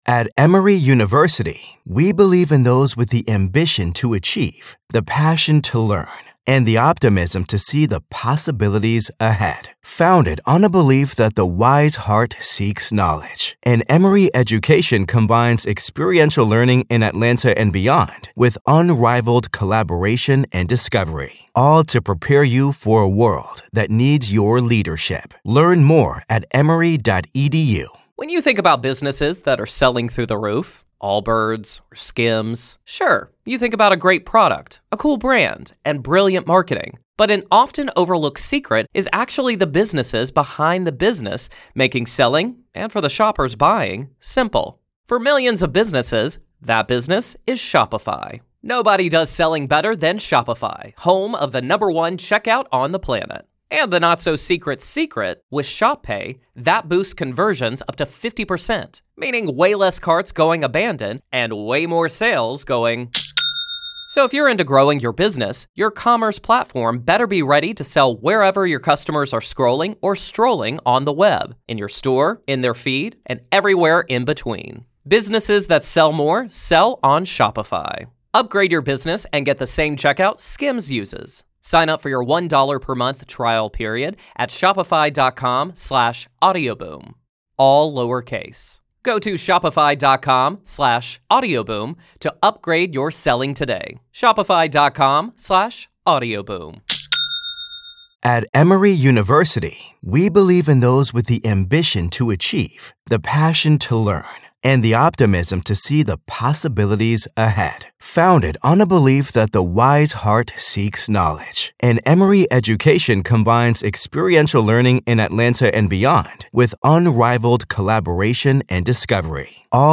One caller recounts the condo’s escalating chaos—and her own love‑hate obsession with the paranormal.